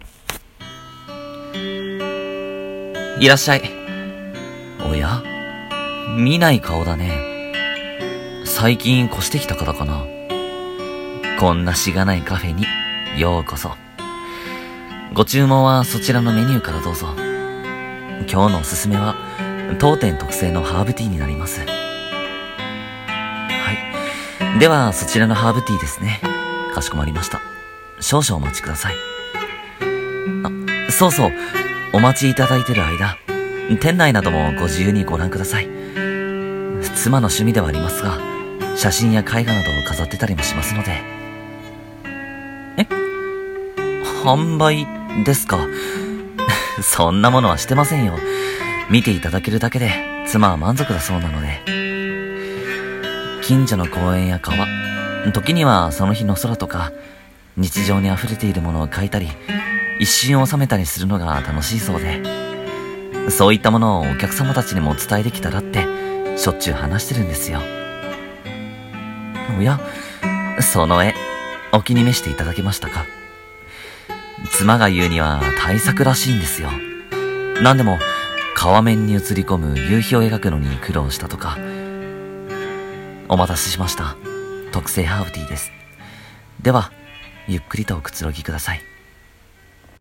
1人声劇【カフェ・グランドチェリー】